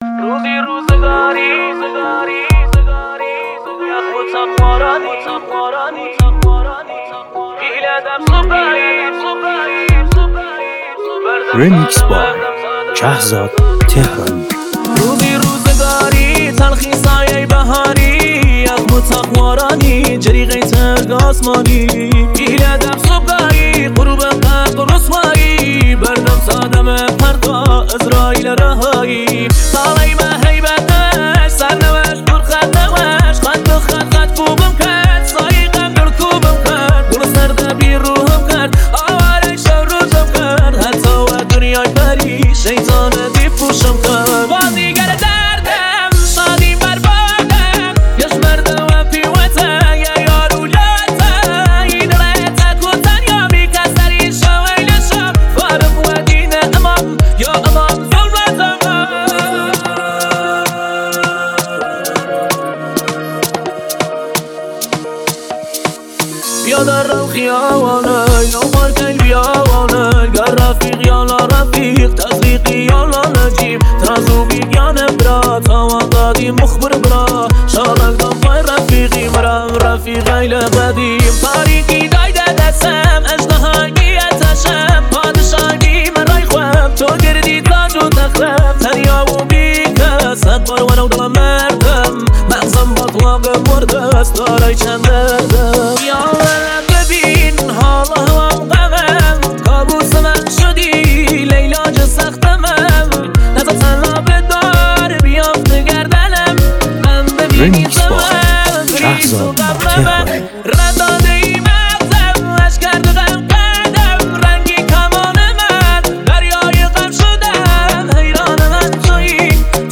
• ریمیکس